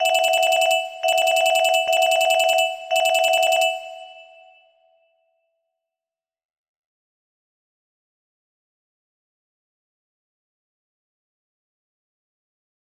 telephone music box melody
RIIIIIIIIIIIIIIIIIIIIIIING RIIIIIIIIIIIIIIIIIIIIIIIIIIIIIIING